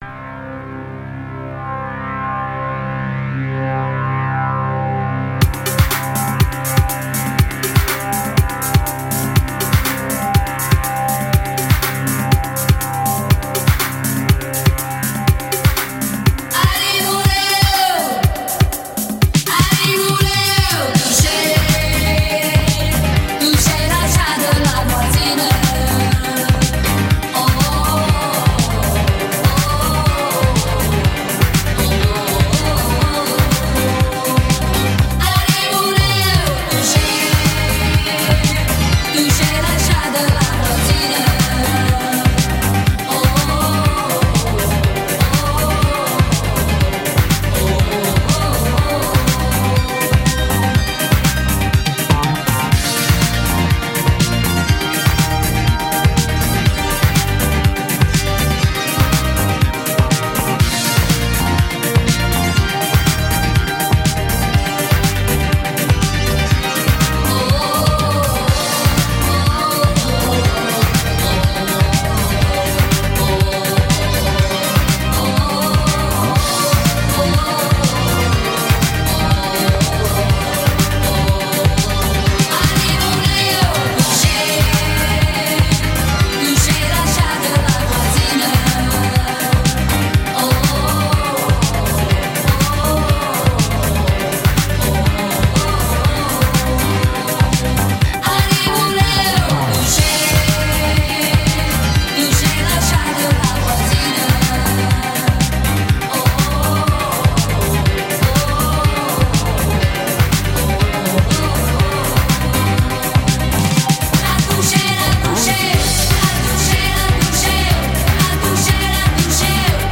フランス語の女性ヴォーカルやピアノ/シンセ・ワークが耳に残る、ハウステンポのエレクトリックなイタロ・ブギー古典です。